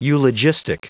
Prononciation du mot eulogistic en anglais (fichier audio)
Prononciation du mot : eulogistic